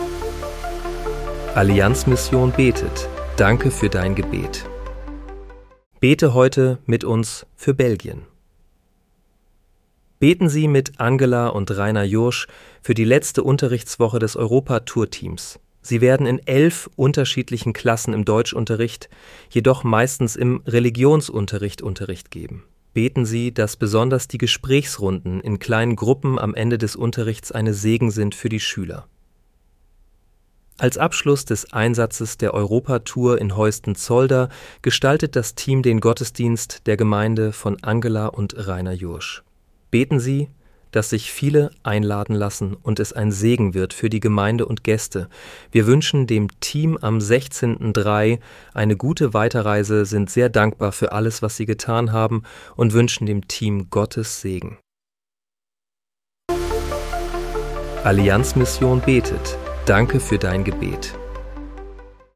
Bete am 13. März 2026 mit uns für Belgien. (KI-generiert mit der